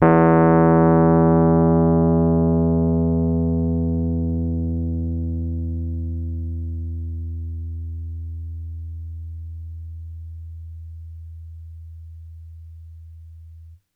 RHODES CL04L.wav